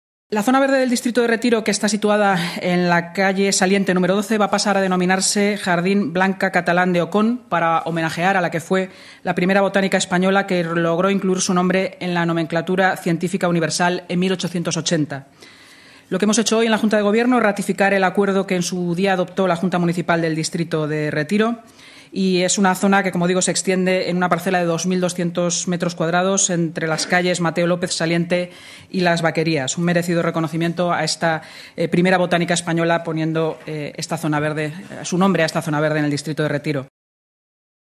Nueva ventana:Así lo ha explicado la portavoz del Gobierno municipal, Inmaculada Sanz, tras la rueda de prensa: